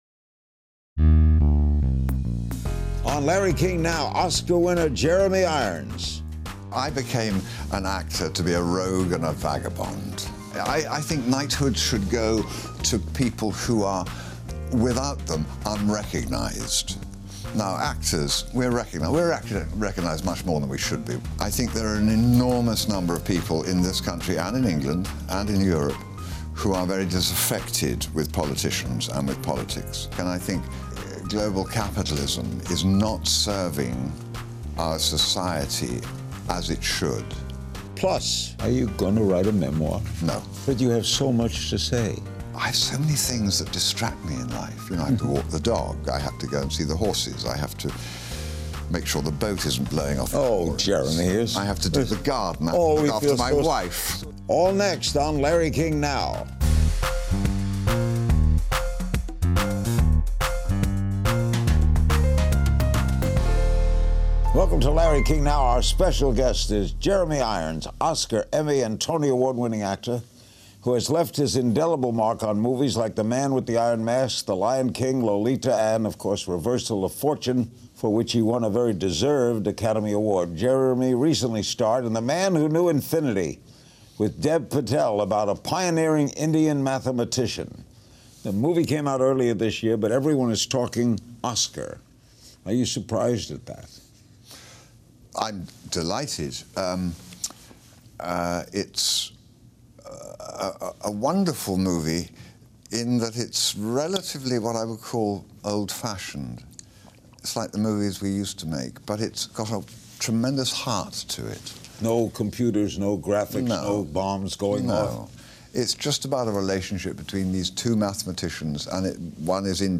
On October 24, 2016, Jeremy Irons sat down with Larry King in Los Angeles for an episode of Larry King Now.